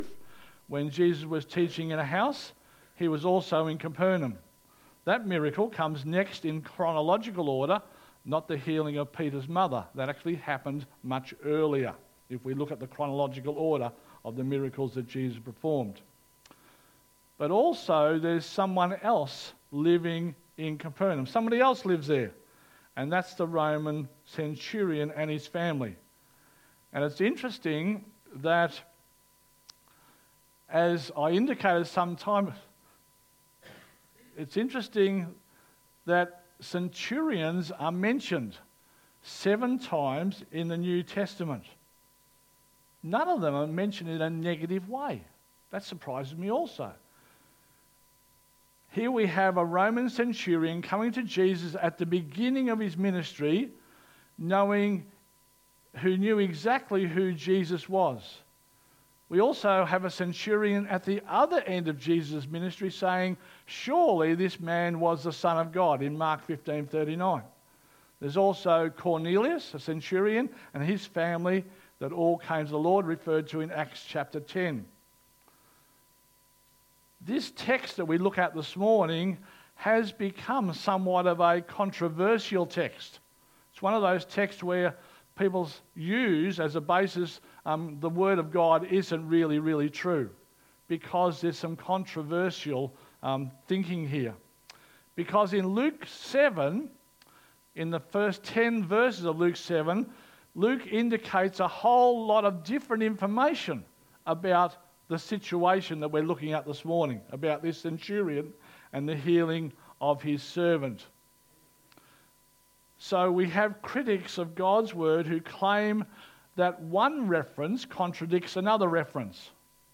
2025 • 18.62 MB Listen to Sermon Download this Sermon Download this Sermon To download this sermon